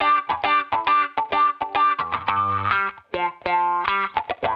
Index of /musicradar/sampled-funk-soul-samples/105bpm/Guitar
SSF_StratGuitarProc2_105G.wav